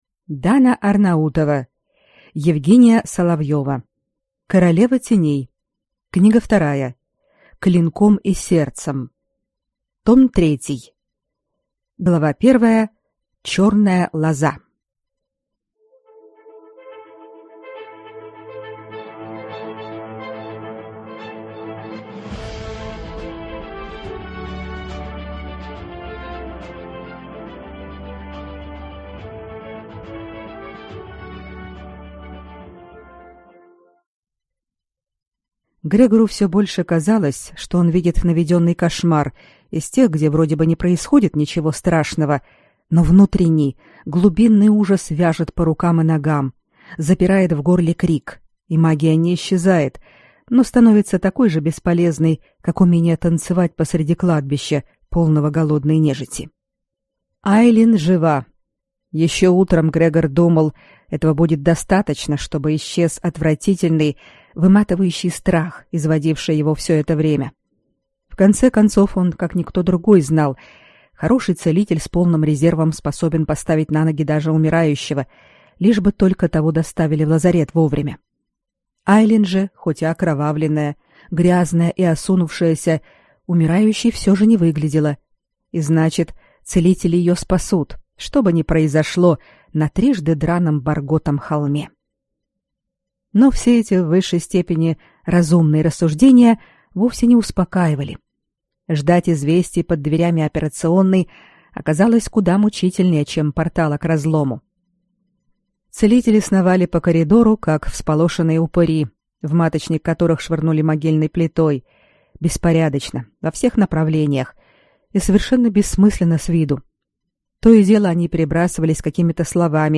Аудиокнига Клинком и сердцем. Том 3 | Библиотека аудиокниг